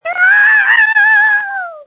This is just a sample of the many screams recorded on January 20, 2021.
• When you call, we record you making sounds. Hopefully screaming.